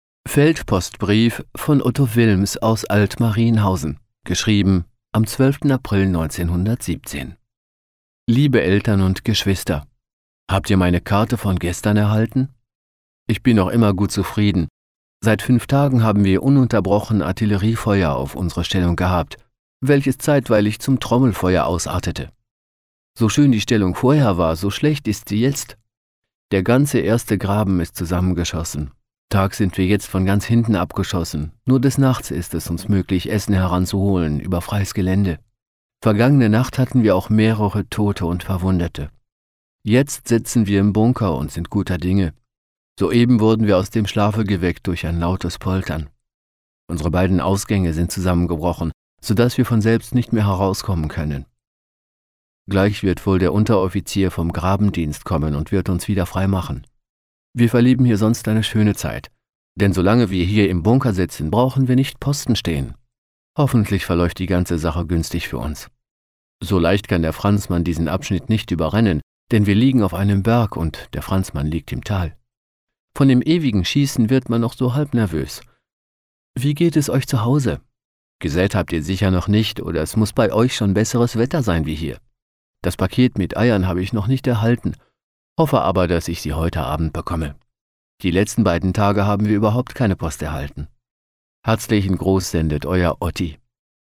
Sprachproben
VoiceOver